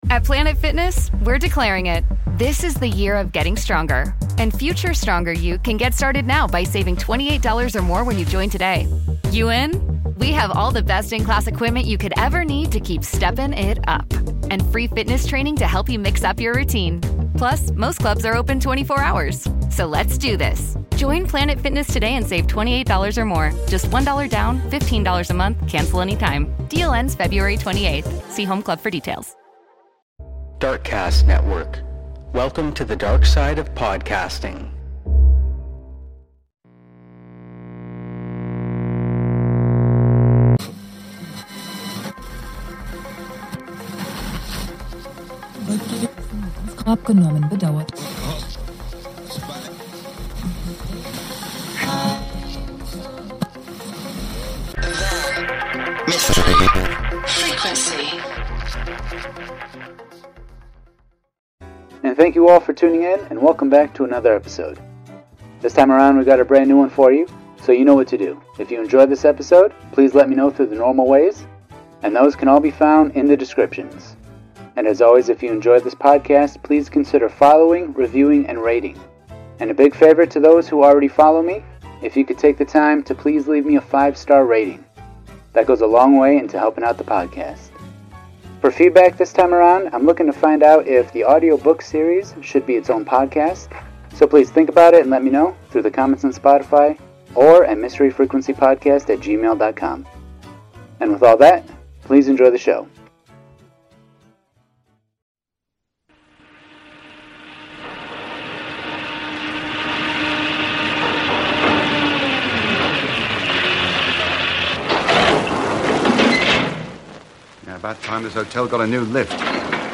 Old Time Radio Show | Casey Crime Photographer Vol 4 | Classic Detective Audio Drama A photo is worth a thousand words, and Casey proves this to be true. He makes it his mission to right the wrongs that he captures in each photo.